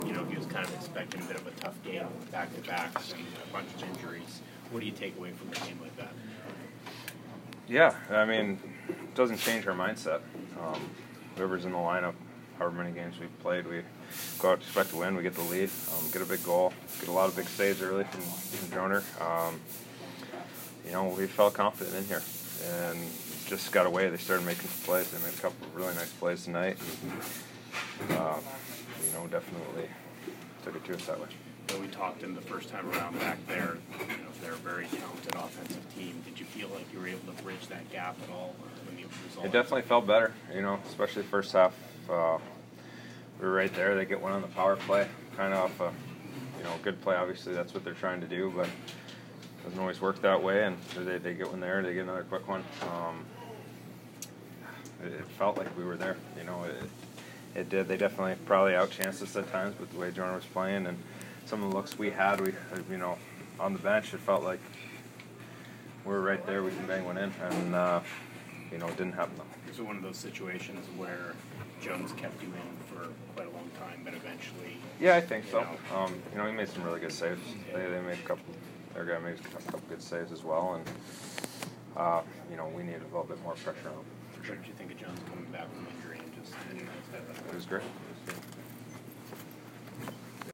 Joe Thornton Post-Game 12/2